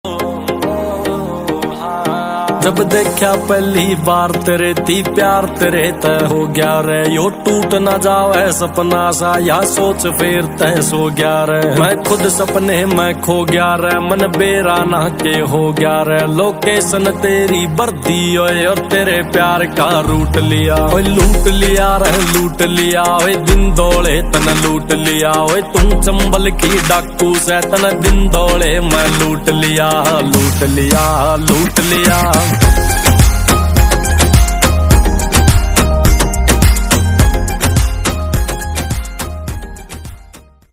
haryanvi song